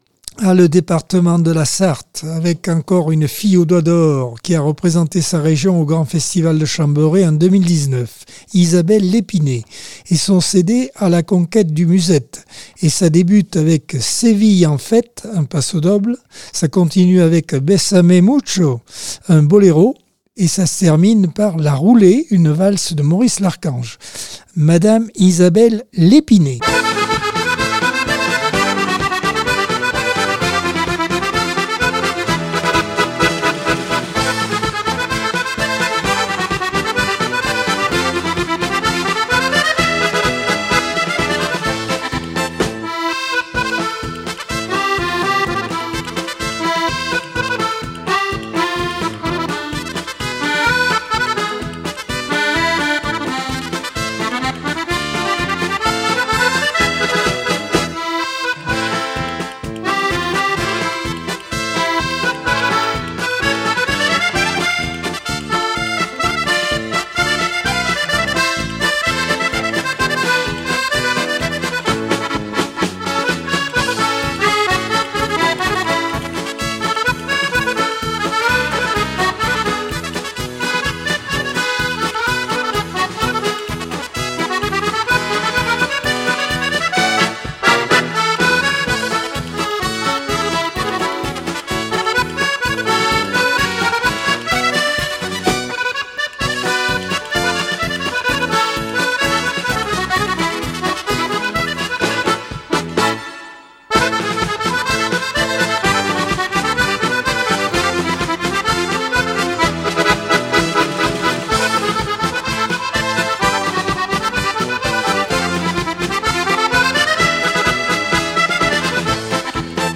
Accordeon 2024 sem 24 bloc 4 - Radio ACX